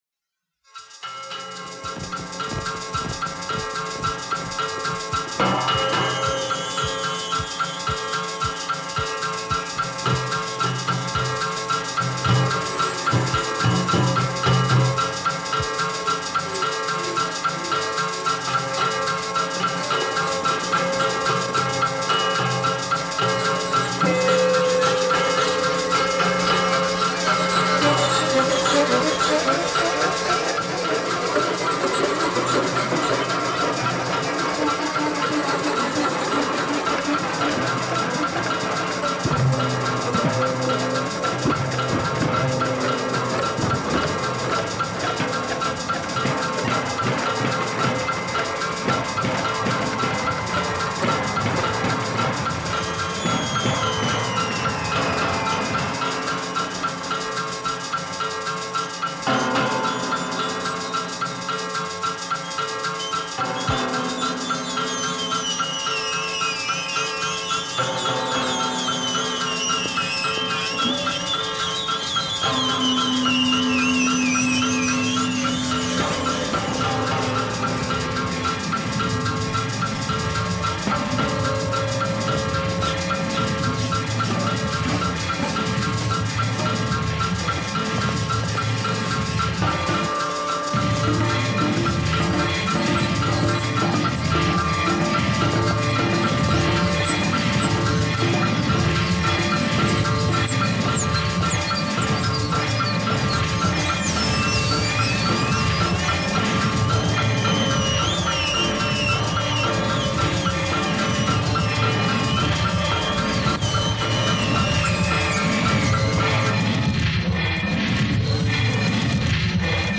J'adore ce genre d'ambiances